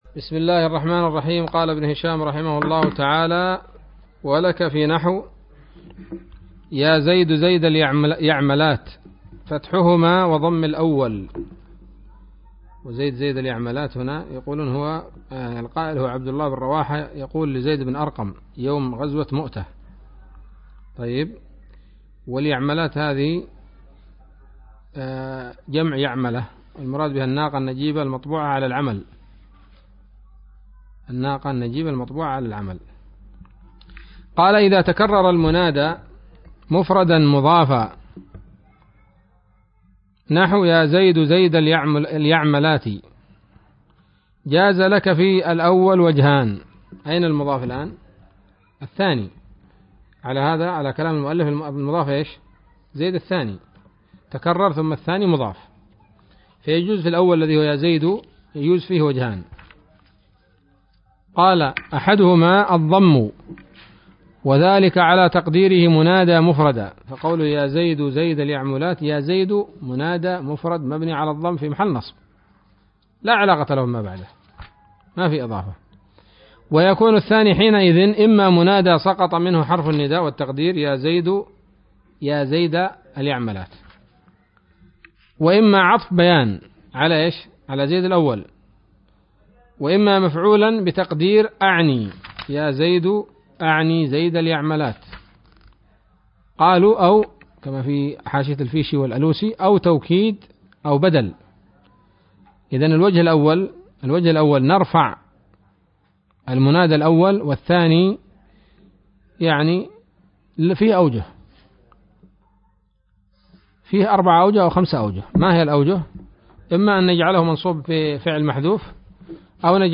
الدرس الثامن والثمانون من شرح قطر الندى وبل الصدى